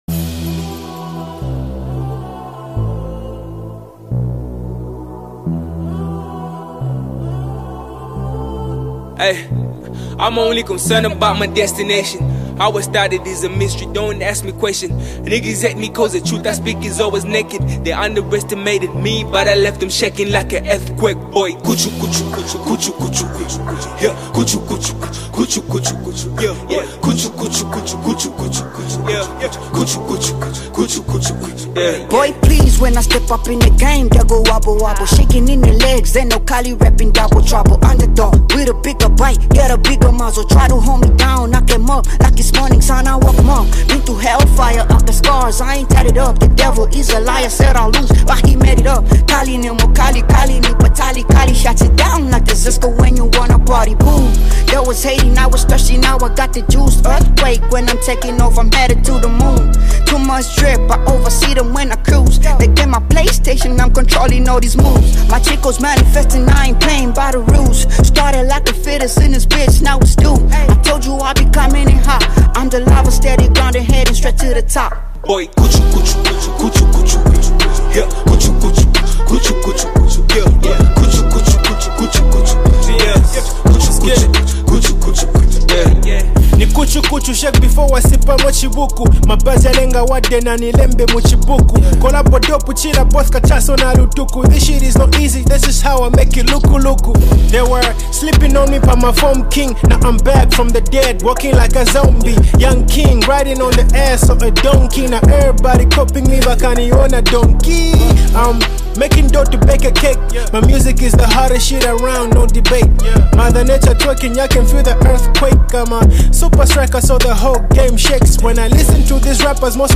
This energetic song
is a lively, dance-worthy anthem